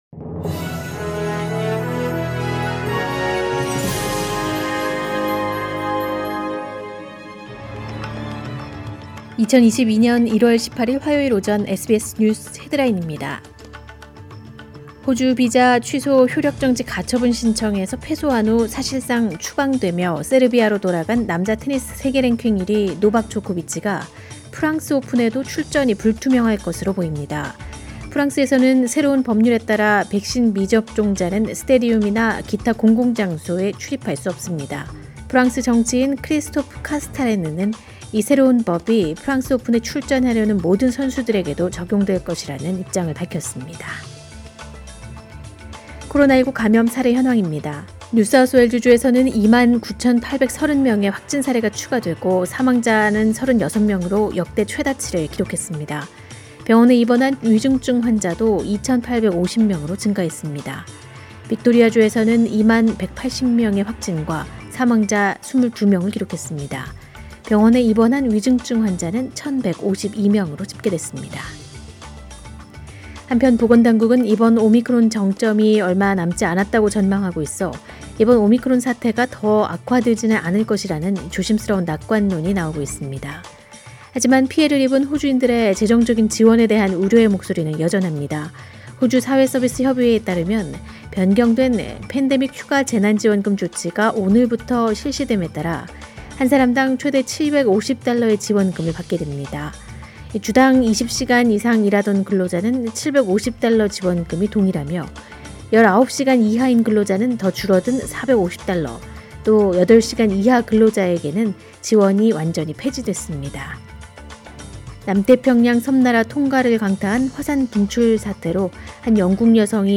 2022년 1월 18일 화요일 오전의 SBS 뉴스 헤드라인입니다.